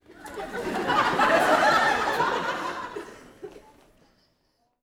Audience Laughing-04.wav